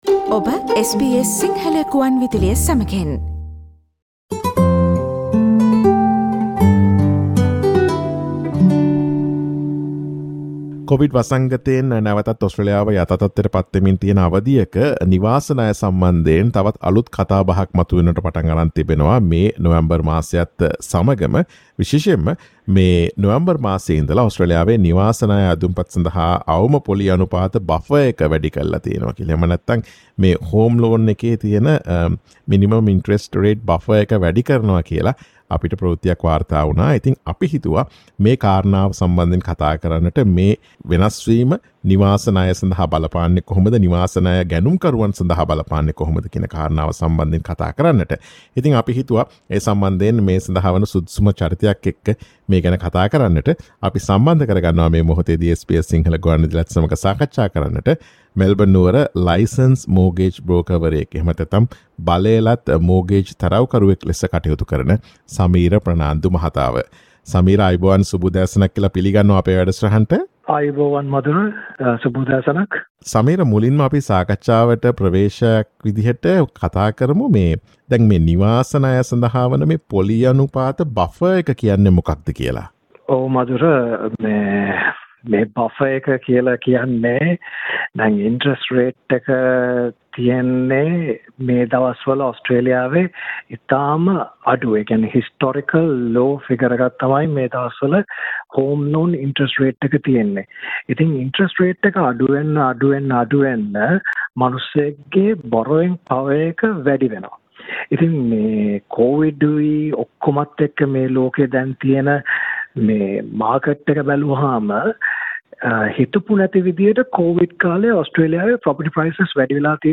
නොවැම්බර් සිට ඔස්ට්‍රේලියාවේ නිවාස ණය අයදුම්පත් සඳහා අවම පොලී අනුපාත buffer එක වැඩි කර තිබේ. එය නිවාස ණය ගැනුම්කරුවන්ට බලපාන අයුරු පිළිබඳ SBS සිංහල ගුවන් විදුලිය සිදුකළ සාකච්ඡාවට සවන් දෙන්න.